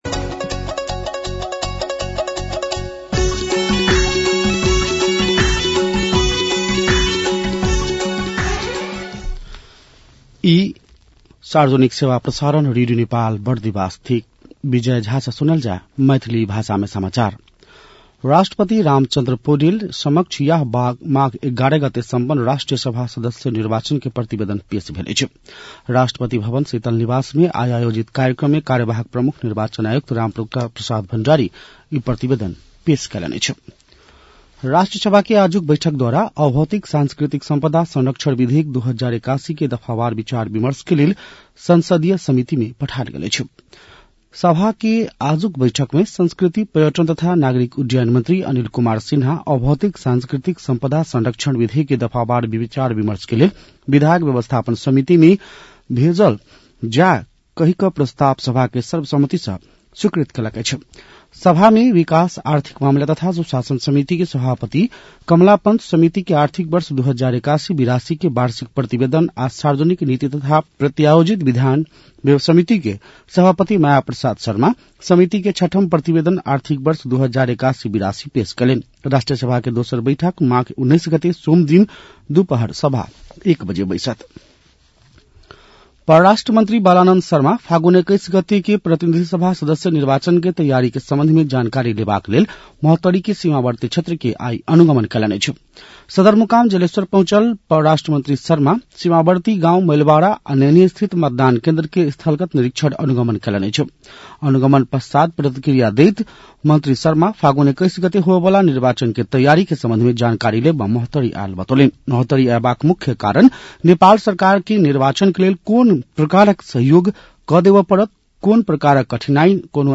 मैथिली भाषामा समाचार : १४ माघ , २०८२
6-pm-maithali-news-.mp3